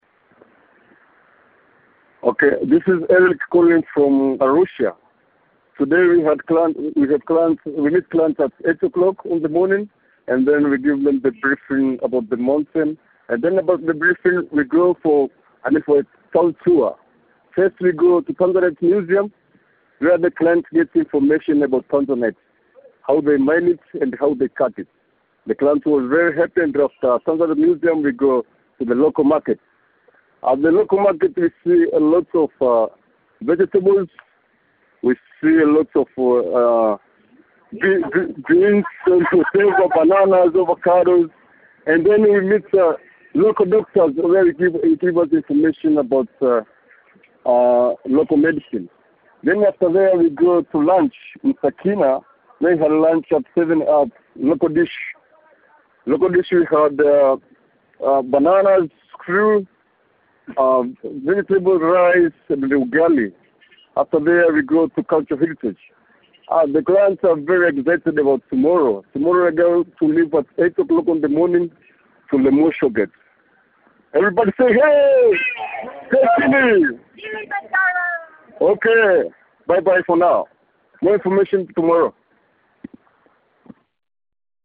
The team sounds great and having fun with the anticipation as it builds for the climb ahead.